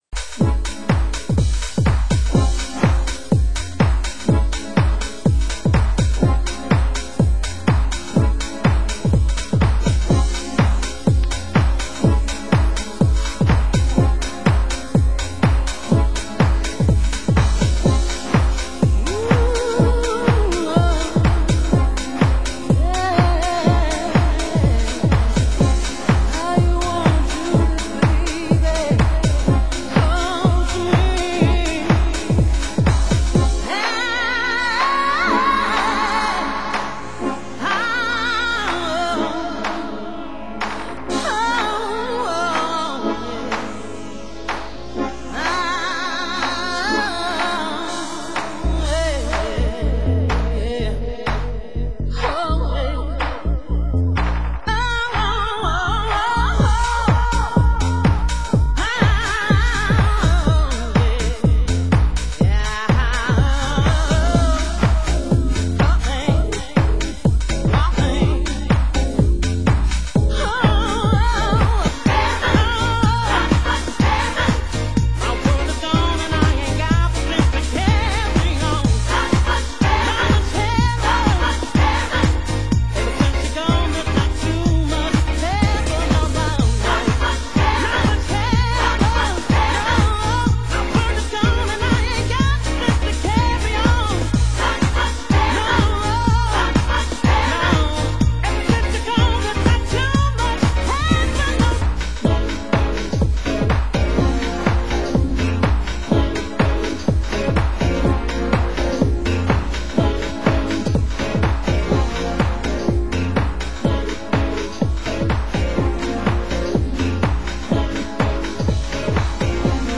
Genre: UK House
New York Deep Mix, Original Classic Mix, New York Deep Dub